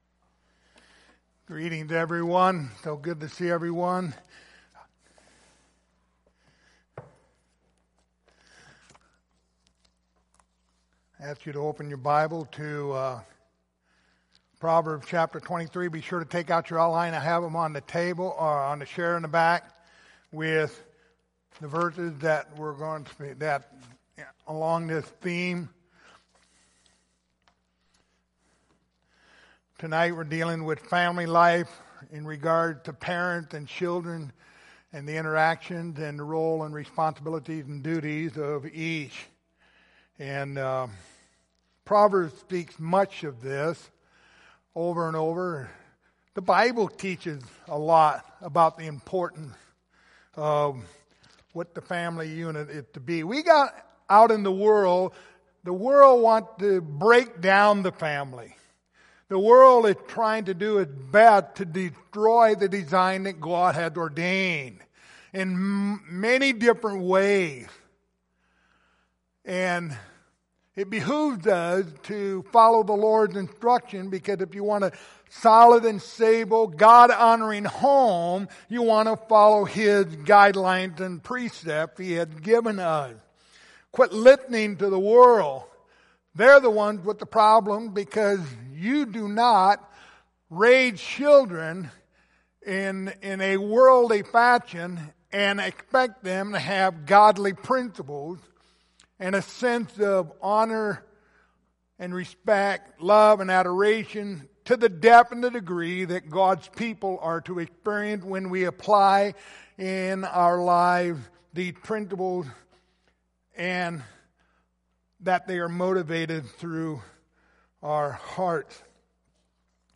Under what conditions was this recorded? Series: The Book of Proverbs Passage: Proverbs 10:1 Service Type: Sunday Evening